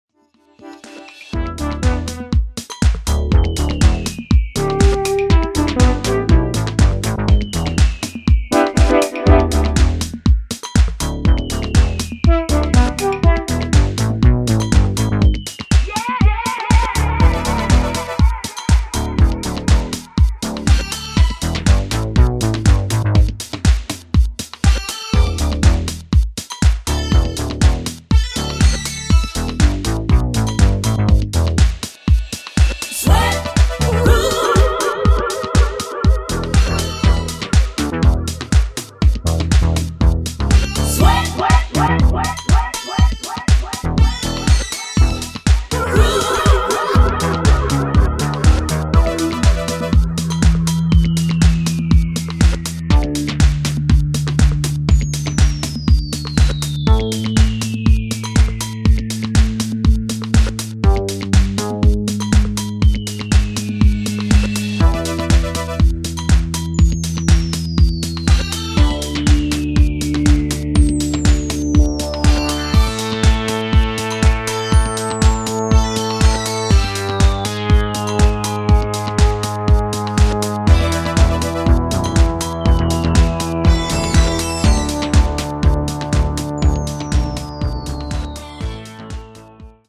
DISCO DUB
ジャンル(スタイル) HOUSE / SOULFUL HOUSE / DISCO HOUSE